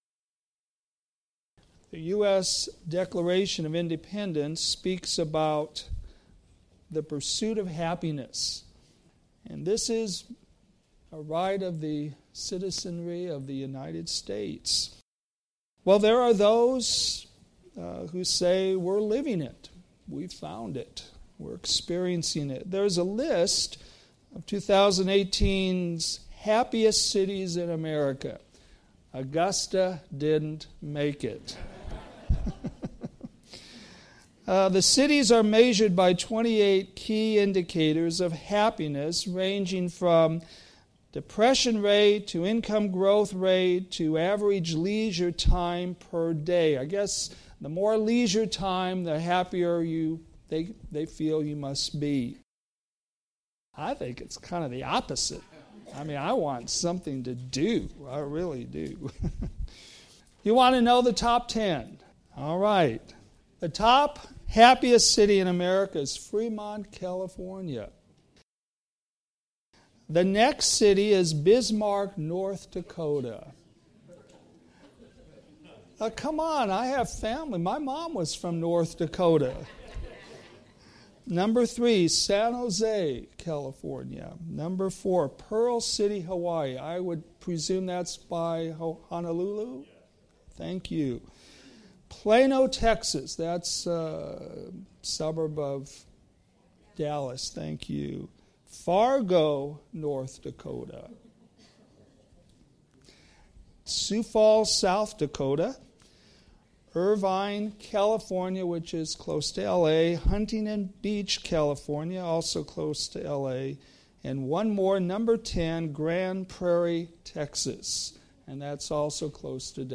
Sermons based on New Testament Scripture